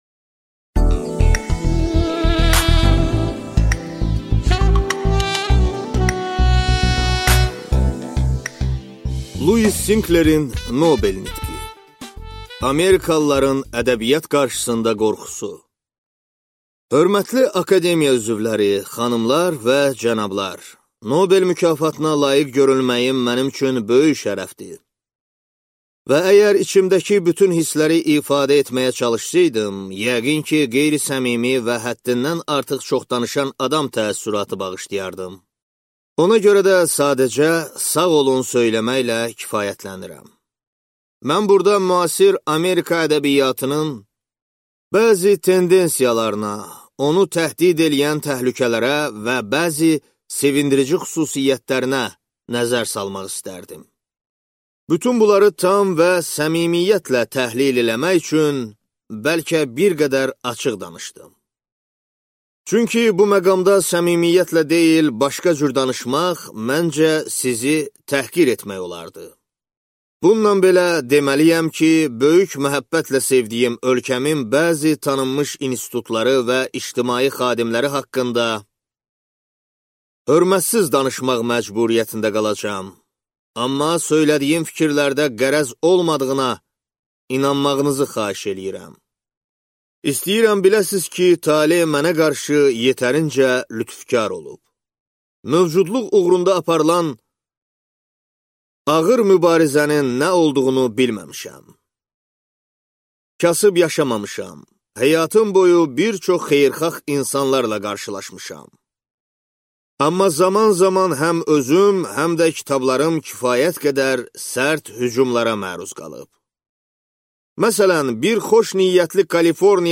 Аудиокнига Lüis Sinklerin nobel nitqi | Библиотека аудиокниг